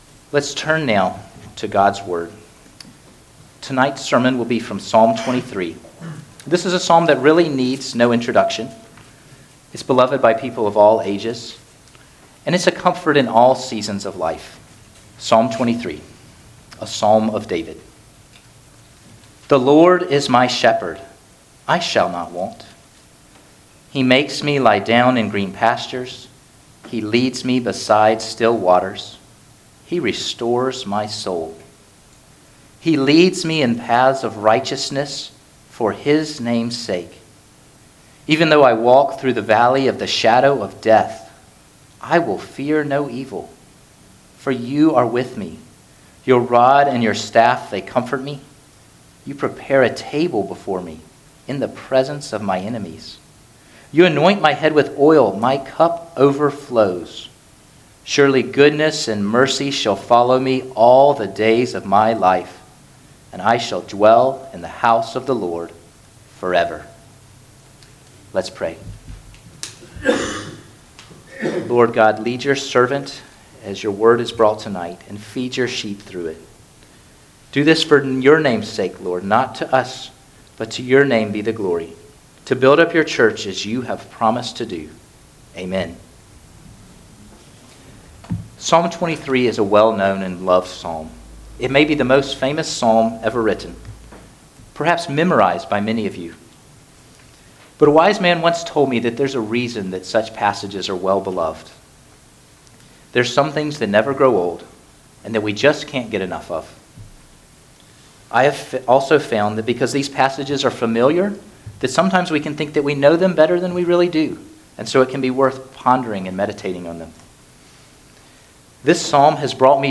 Guest Preachers Passage: Psalm 23 Service Type: Sunday Evening Service Download the order of worship here .